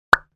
Download Free Retro 8 Bit Sound Effects | Gfx Sounds
Arcade-8-bit-message.mp3